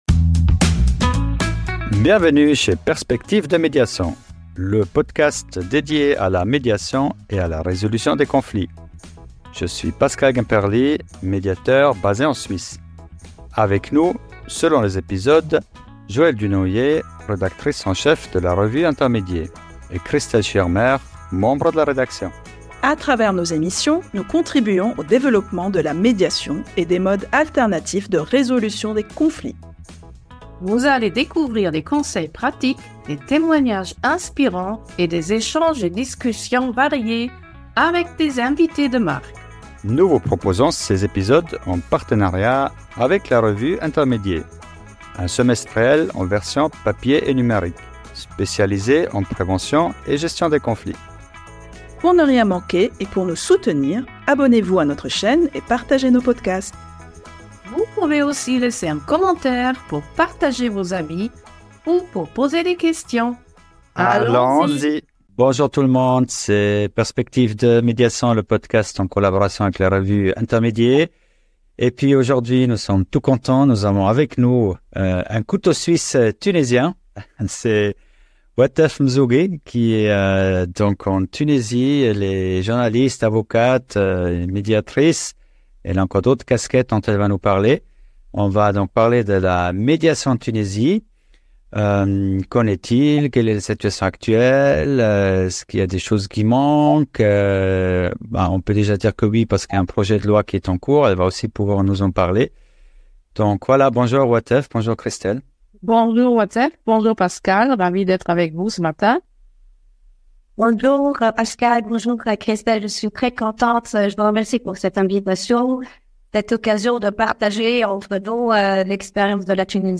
💬 Un échange riche, humain et passionnant sur les liens entre justice, société et transformation personnelle.